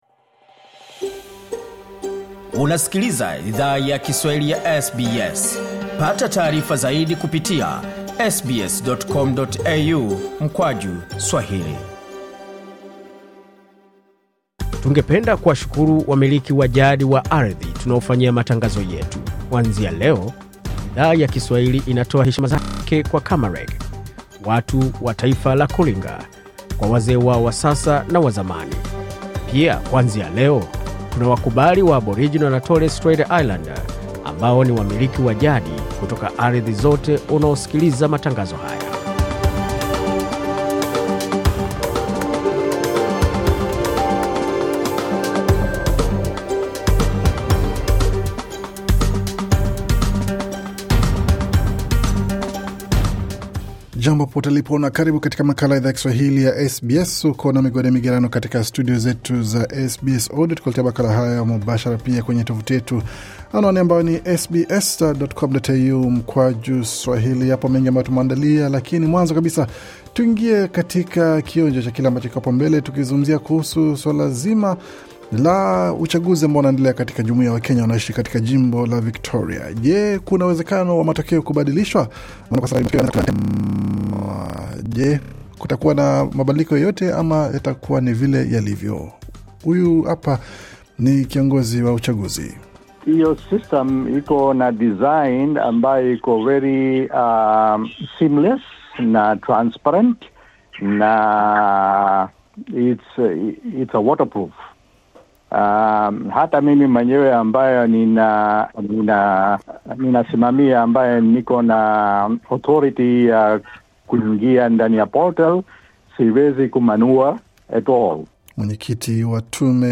Taarifa ya Habari 27 Juni 2025